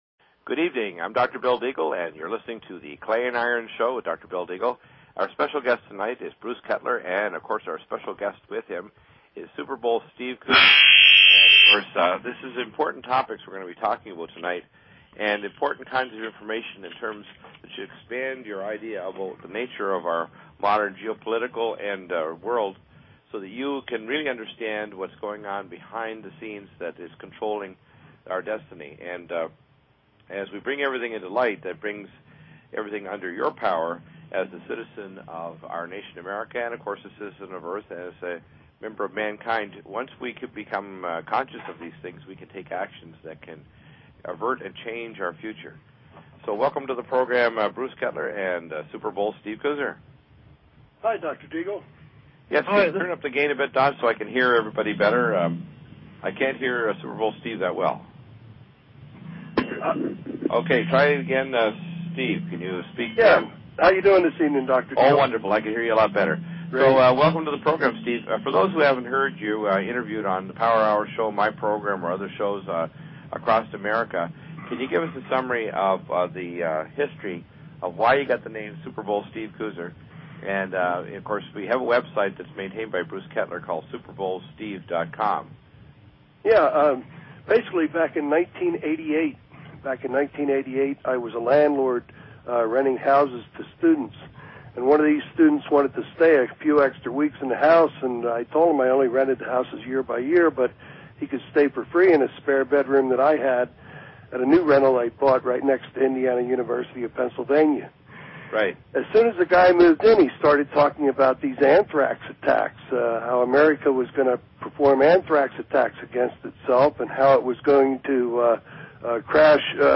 Talk Show Episode, Audio Podcast, Clay_Iron and Courtesy of BBS Radio on , show guests , about , categorized as
Interview w